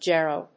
/ˈdʒɛro(米国英語), ˈdʒerəʊ(英国英語)/